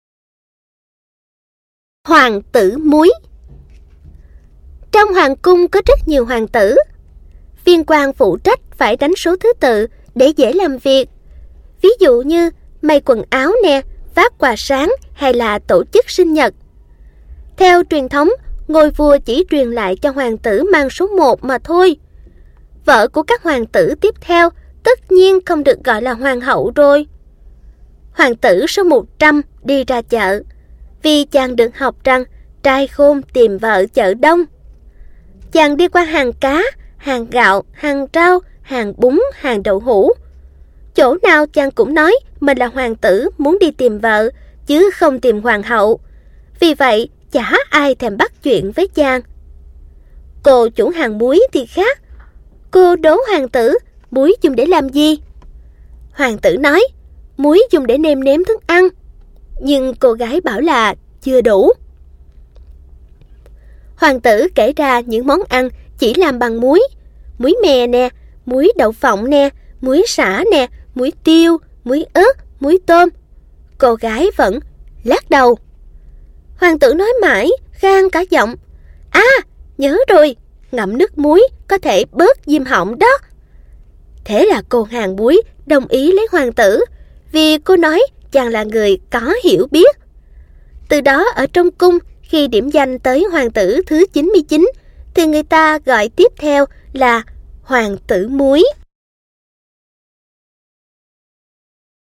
Sách nói | Xóm đồ chơi